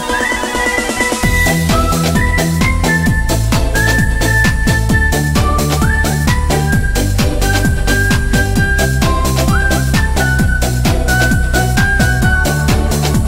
Chưa rõ Genre: Nhạc remix Giới thiệu